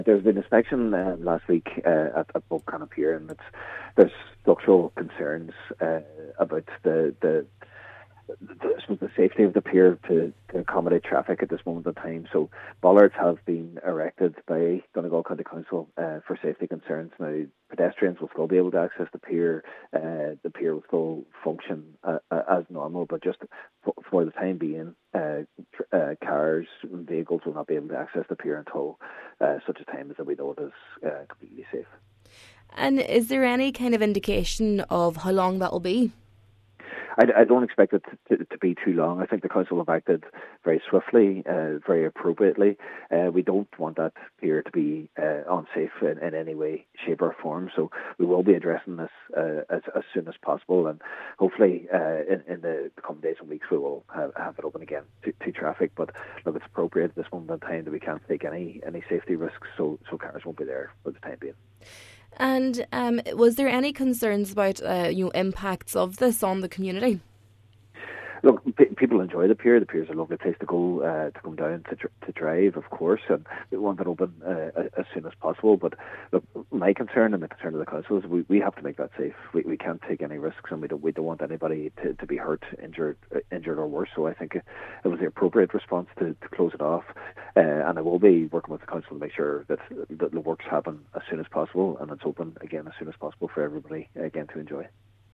Cllr Jack Murray says access by sea will not be affected, and the summer ferry service will continue as normal: